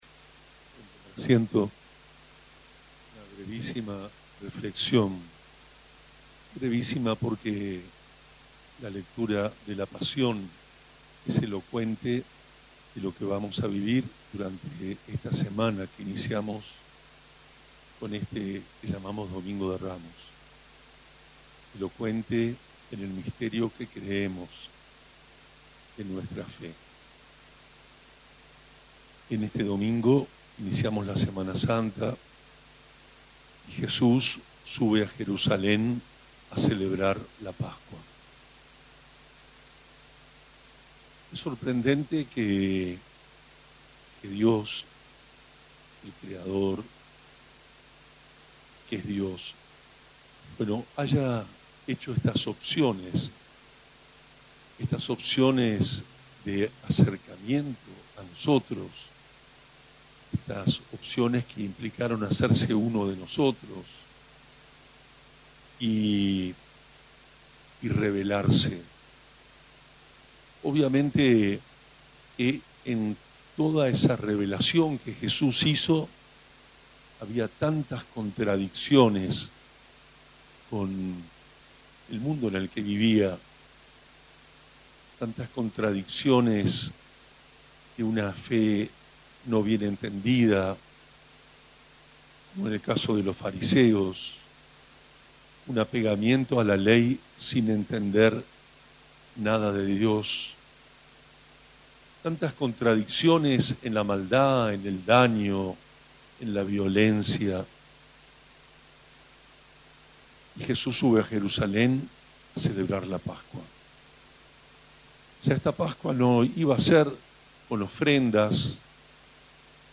En un ambiente colmado de devotos, el obispo brindó una homilía centrada en estos aspectos esenciales del misterio pascual, invitando a la comunidad a vivir con mayor profundidad este tiempo litúrgico.
La bendición de los ramos, realizada en la Plaza San Martín, y la Santa Misa, celebrada en la explanada de la Catedral San José, en la Plaza 9 de Julio, fueron transmitidas en vivo a través de Radio Tupa Mbae y el Facebook de la emisora, con el respaldo de la Pastoral de Comunicación, que también compartió la transmisión.